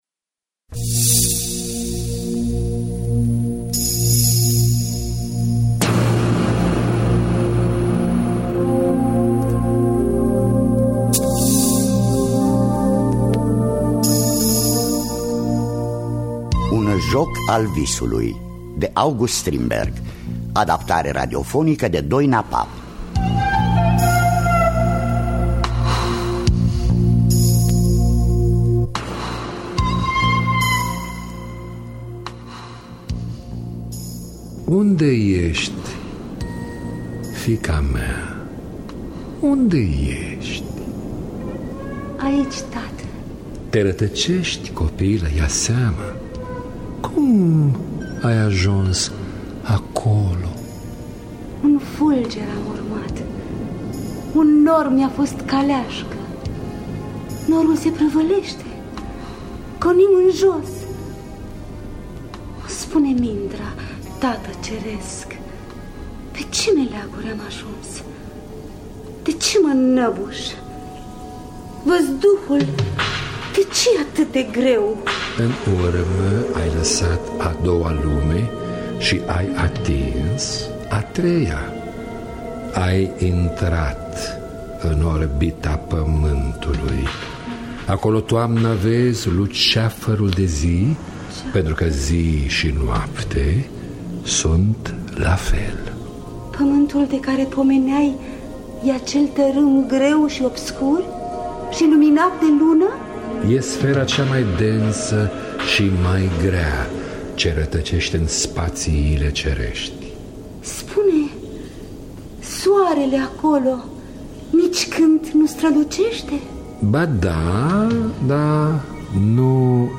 Un joc al visului de August Strindberg – Teatru Radiofonic Online
Adaptarea radiofonică